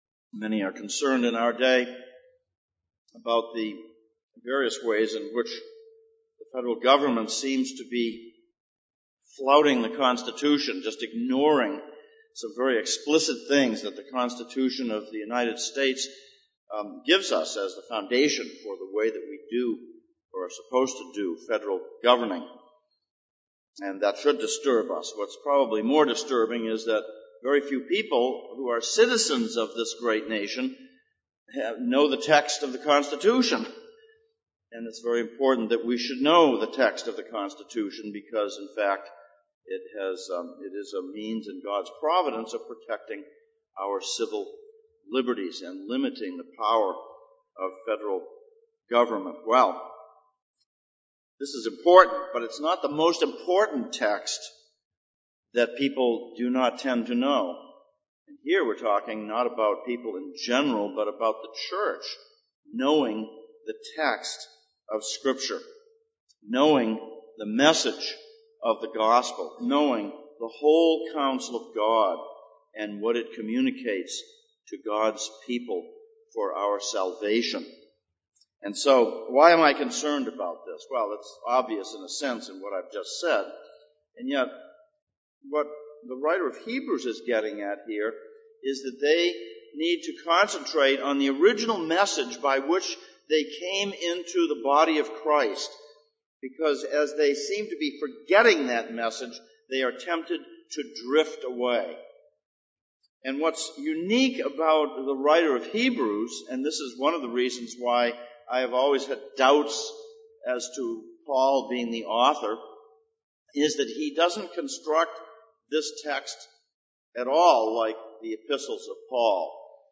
Hebrews Passage: Hebrews 2:1-4, Deuteronomy 17:1-13 Service Type: Sunday Morning